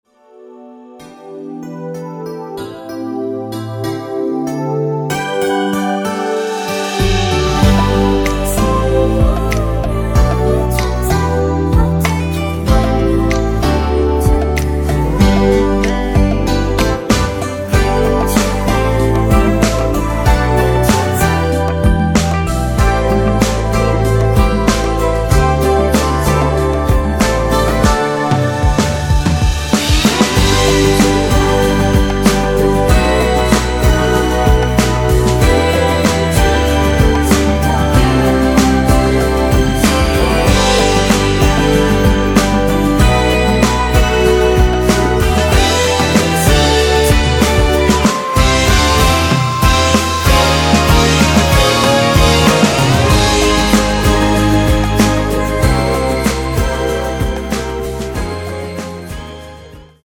여성분이 부르실수 있는키의 MR입니다.
원키에서(+3)올린 코러스 포함된 MR입니다.(미리듣기 참고)
앞부분30초, 뒷부분30초씩 편집해서 올려 드리고 있습니다.